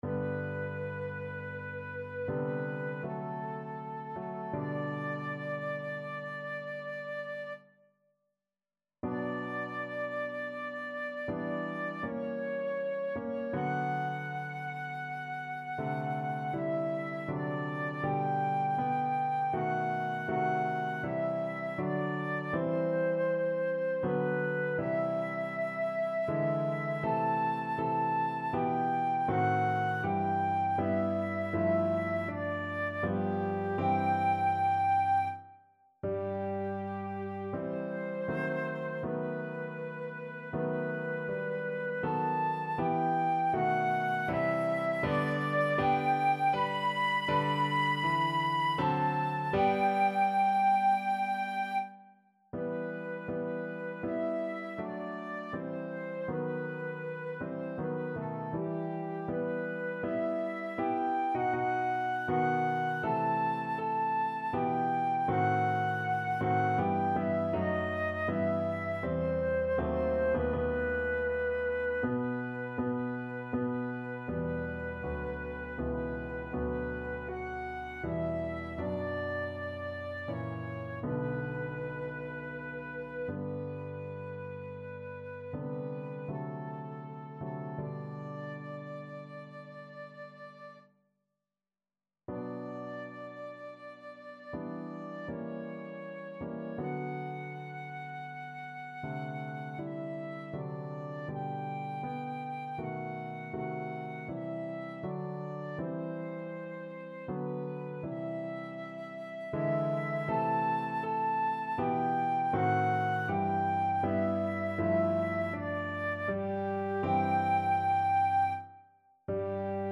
~ = 80 Andante ma non lento
3/4 (View more 3/4 Music)
Classical (View more Classical Flute Music)